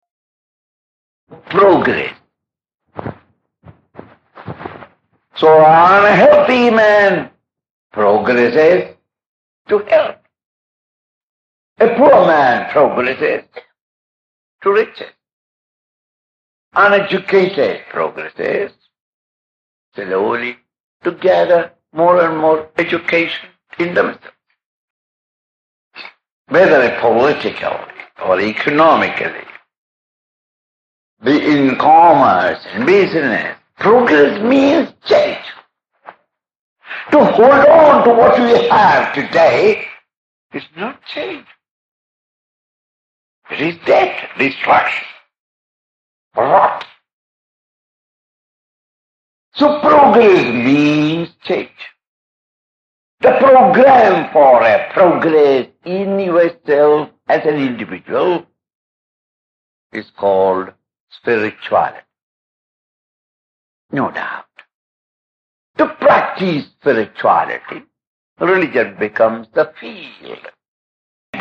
Last Talks on Gita chapter 16&17- MP3